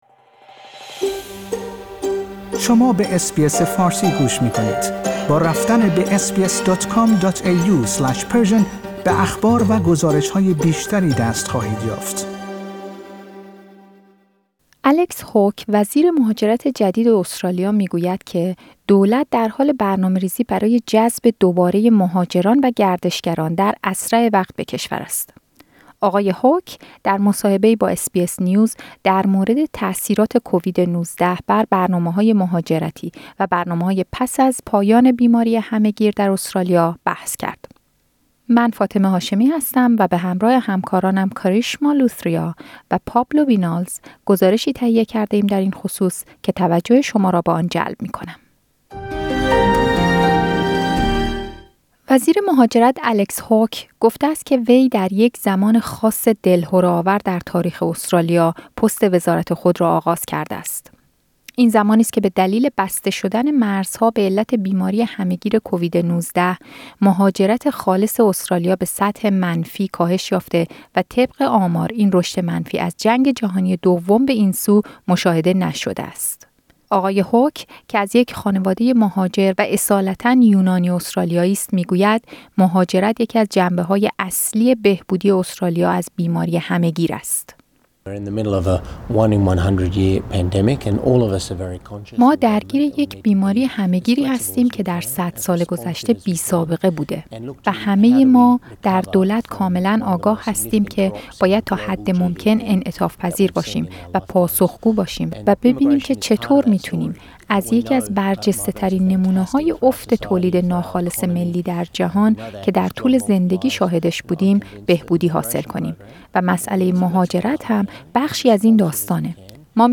الکس هاوک، وزیر مهاجرت جدید استرالیا می‌گوید که دولت در حال برنامه‌ریزی برای اجازه سفر مهاجران و گردشگران در اسرع وقت به کشور است. آقای هاوک در مصاحبه ای با SBS News در مورد تأثیرات کووید-۱۹ بر برنامه‌های مهاجرتی و برنامه‌های پس از پایان بیماری همه‌گیر در استرالیا بحث کرد.